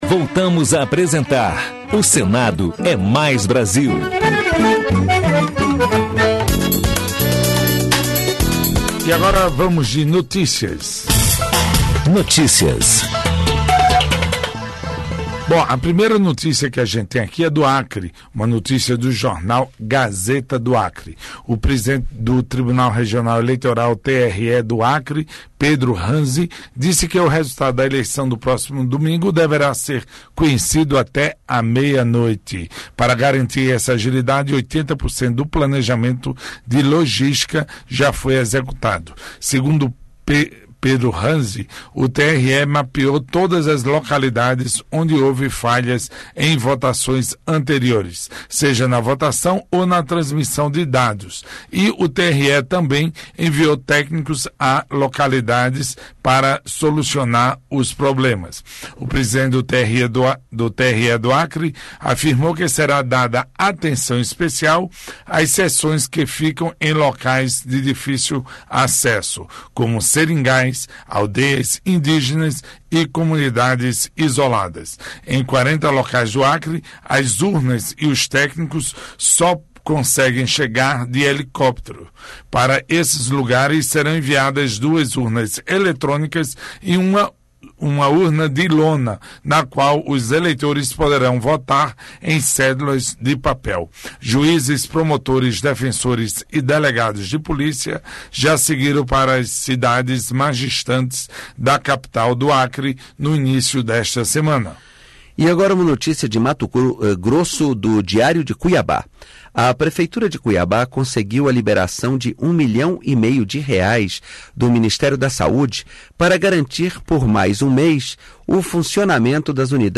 Notícias: giro pelos estados Projeto do Dia: Proposta regulamenta profissão de vigia autônomo
Entrevista Especial